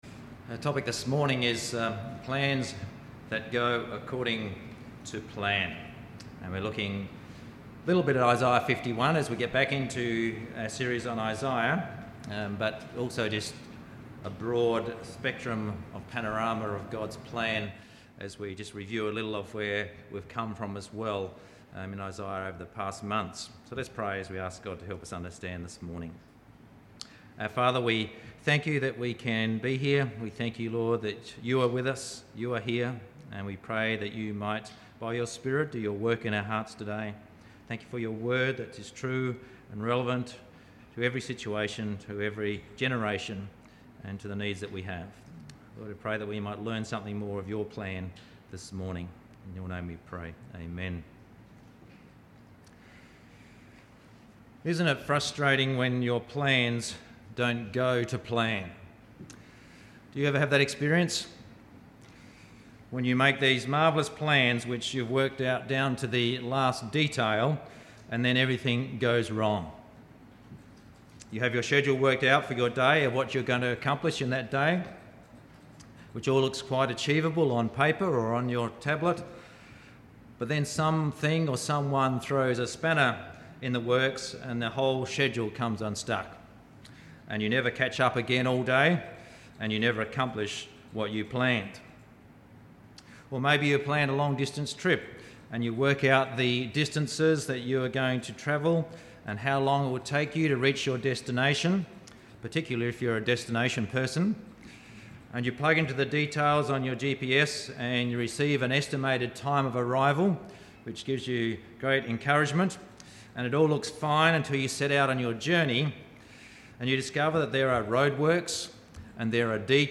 21st January 2018 | Plans That Go According to Plan [Isaiah] – Moree Baptist Church